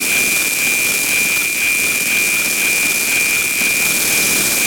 beep-sound.mp3